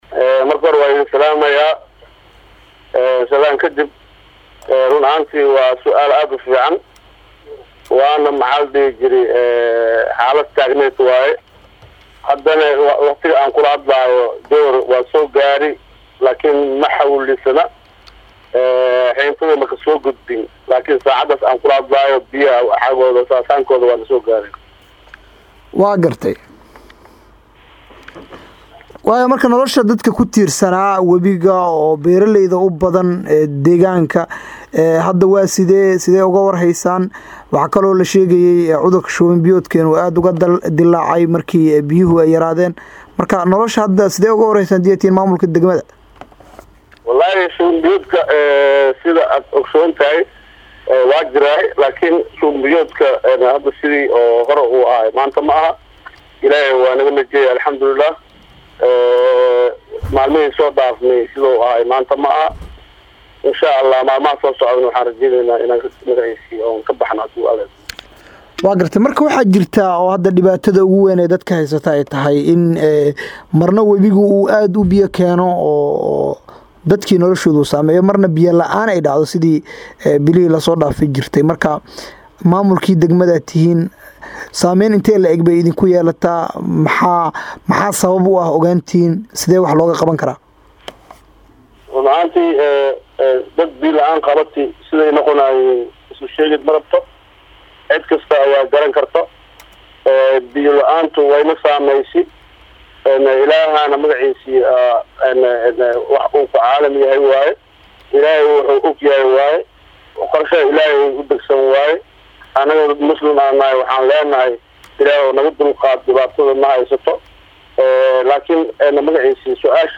Guddoomiyaha Degmada Jowhar ee xaruunta Gobalka shabeelaha dhexe ahna Caasimadda maamulka Hir-shabeele Cismaan Maxamed Muqtaar oo la hadlay Radio Muqdisho
Waraysi-Gudoomiyaha-Degmada-Jowhar-Cismaan-Maxamed-Muqtaar.mp3